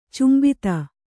♪ cmbita